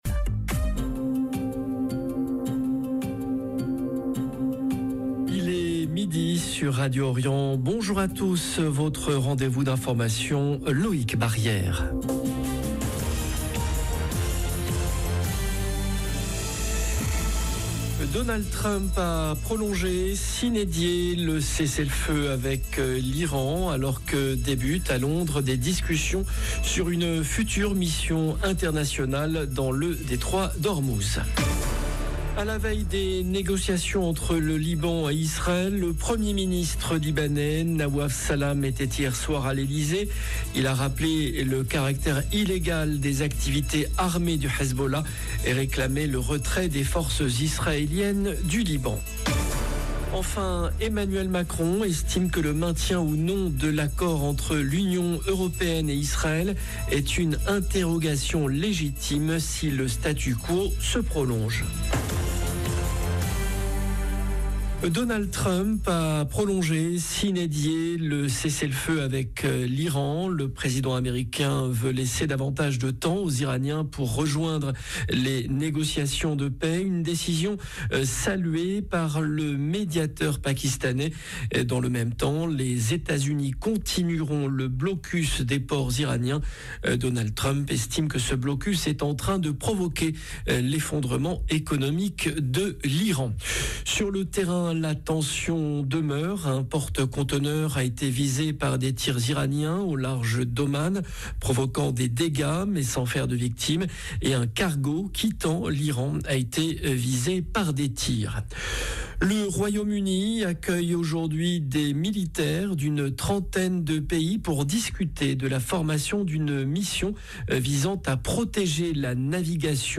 Journal de midi Au sommaire : Donald Trump a prolongé sine die le cessez-le-feu avec l’Iran alors que débutent à Londres des discussions sur une future mission internationale dans le Détroit d’Ormuz.